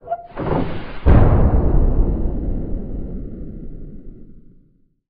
Ambient5.ogg